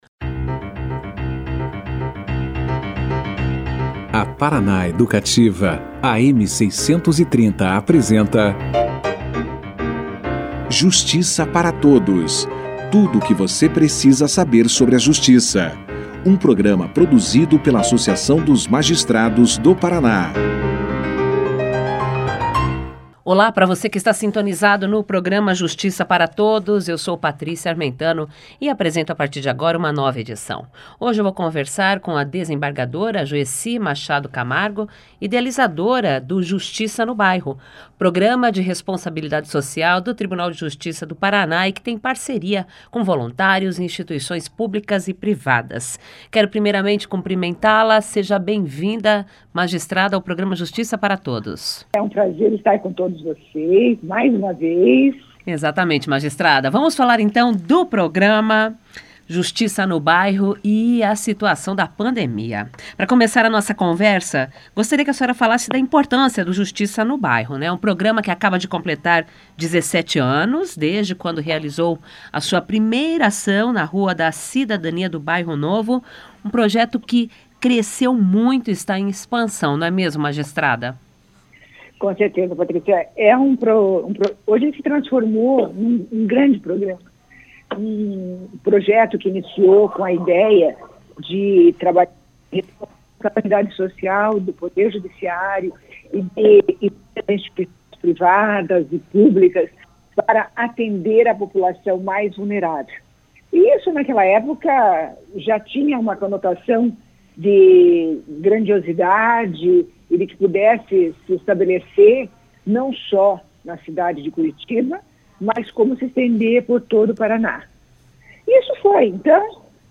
Tema: COVID-19 Entrevistado: Joeci Machado Camargo Coordenadora do Justiça no Bairro, programa de cunho social do Tribunal de Justiça do Paraná, que acaba de completar 17 anos, com a descentralização de atendimentos jurídicos sem custo e a oferta de serviços de cidadania para atender a população mais vulnerável, a Desembargadora Joeci Machado Camargo, fala sobre as ações programadas para este ano, cerca de 70 eventos que foram interrompidos pela pandemia da COVID-19.